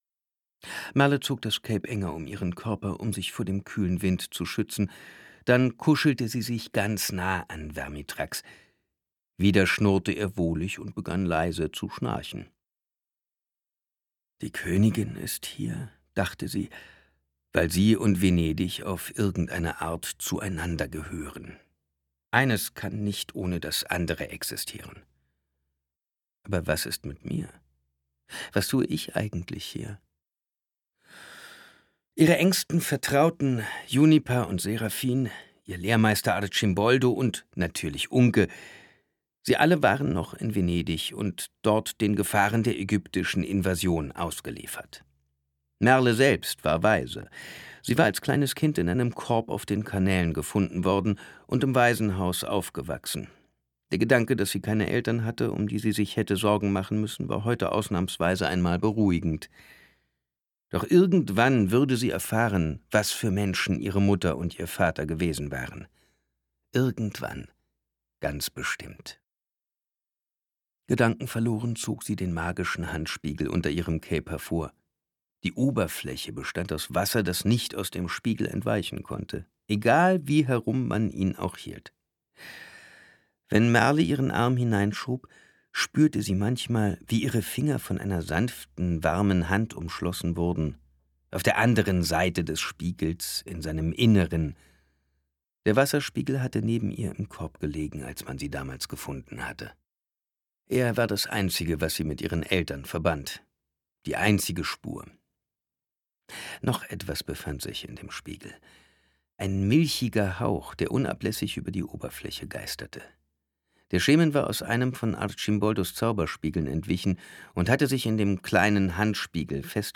Hörbuch: Merle.
Merle. Das Steinerne Licht Merle-Zyklus 2 Kai Meyer (Autor) Simon Jäger (Sprecher) Audio Disc 2020 | 1.